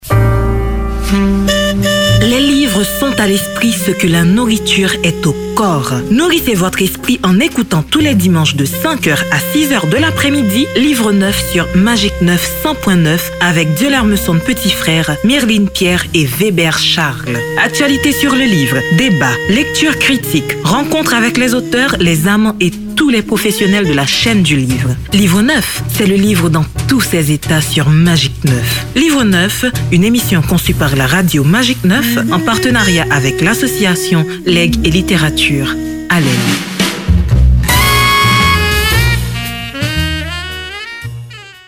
Le spot de l’émission